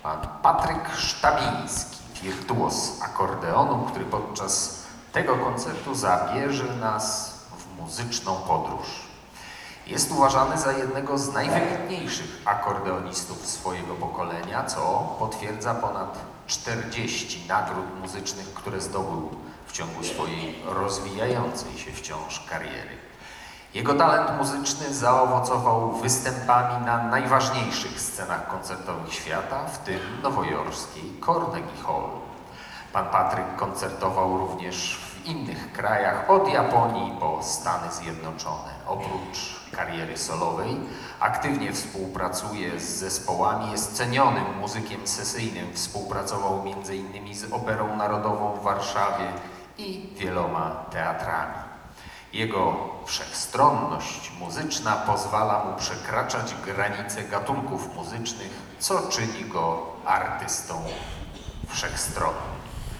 „Jesienne barwy muzyki” – nastrojowy piątkowy koncert w SOK-u